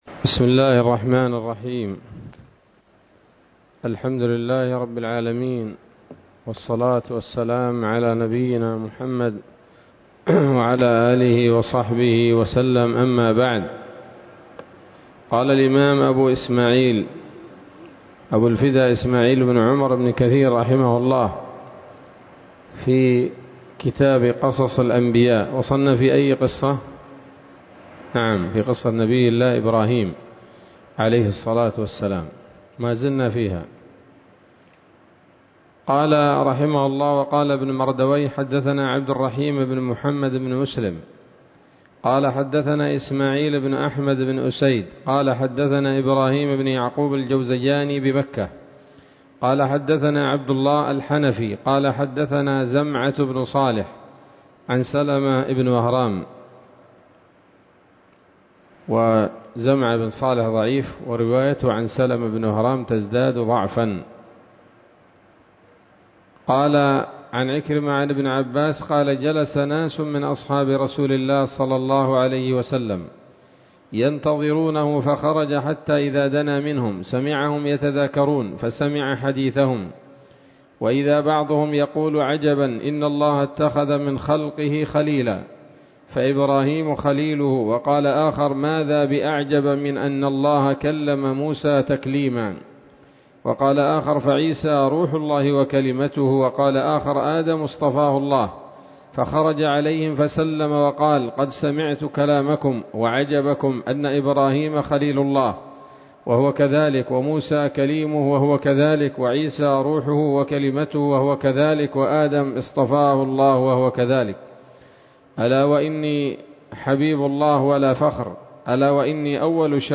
الدرس الرابع والخمسون من قصص الأنبياء لابن كثير رحمه الله تعالى